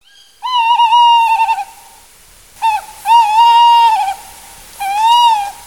Chouette hulotte
hulotte.mp3